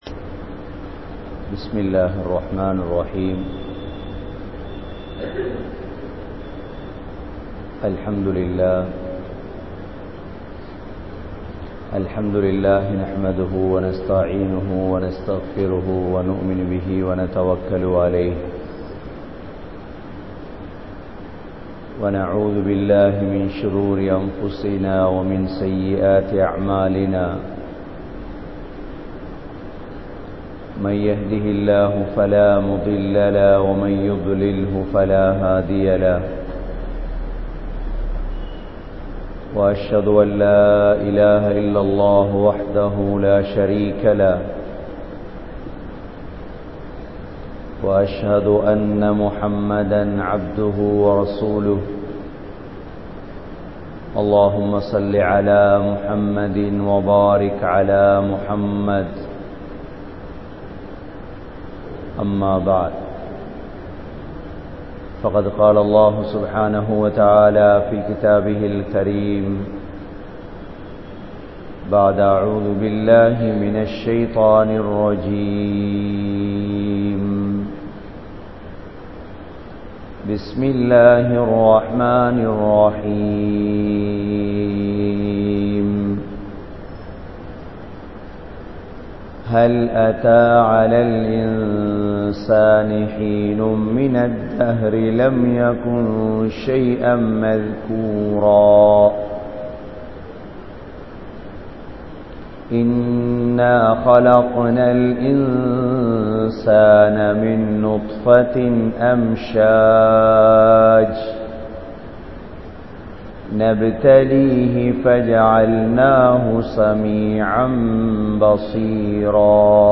Abraarhal Entraal Yaar? (அப்ரார்கள் என்றால் யார்?) | Audio Bayans | All Ceylon Muslim Youth Community | Addalaichenai
Grand Jumua Masjith